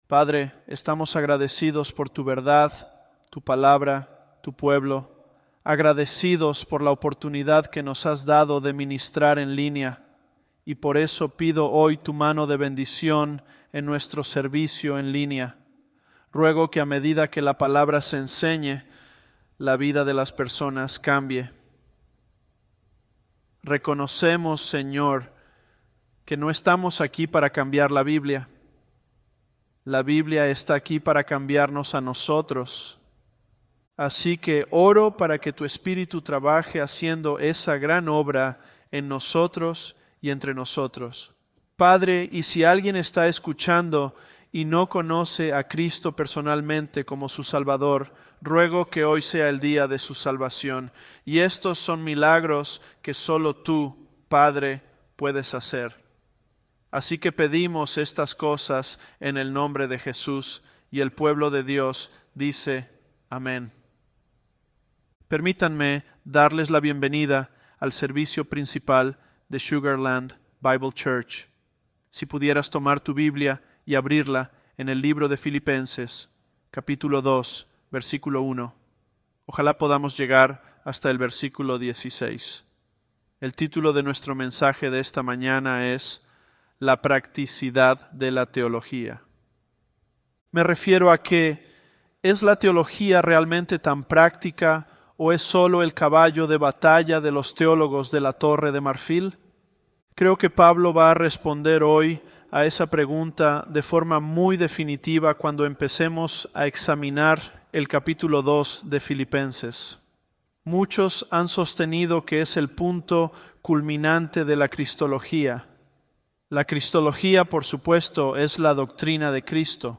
Sermons
Elevenlabs_Philippians004.mp3